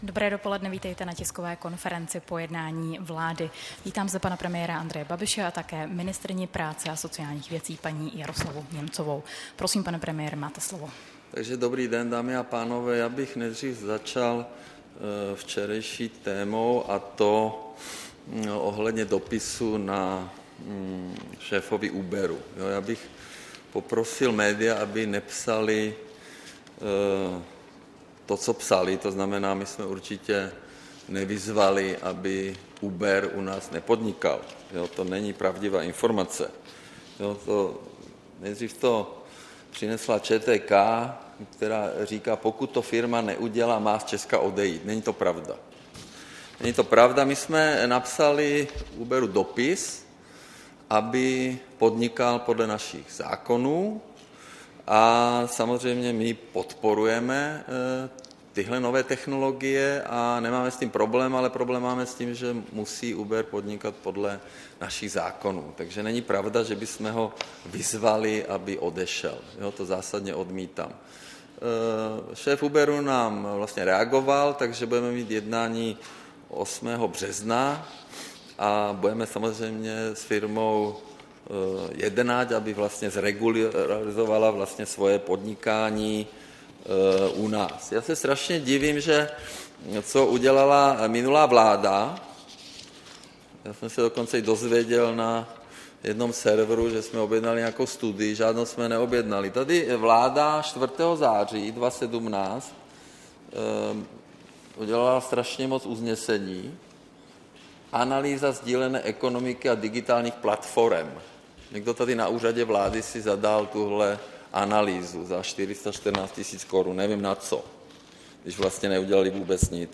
Tisková konference po jednání vlády, 27. února 2018